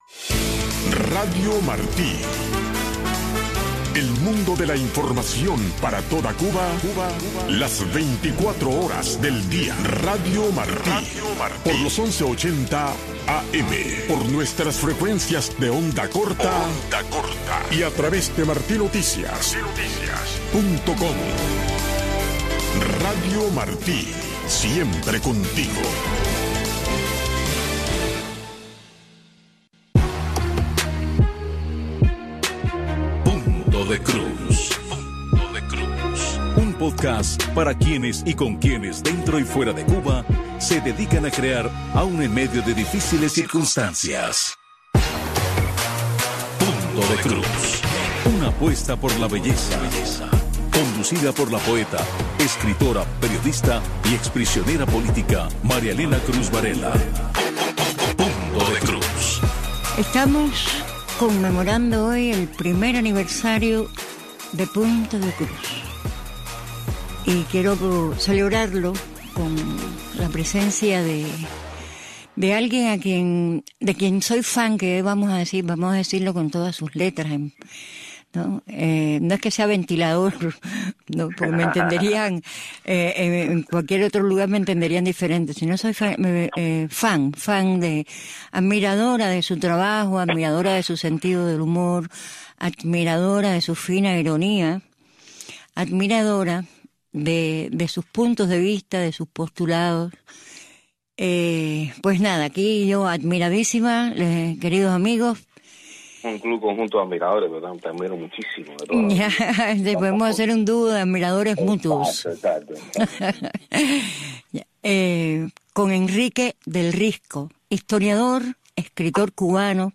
En este espacio informativo de Radio Martí escuche de primera mano los temas que impactan el día a día de los cubanos dentro de la Isla. Voces del pueblo y reportes especiales convergen para ofrecerle una mirada clara, directa y actual sobre la realidad cubana.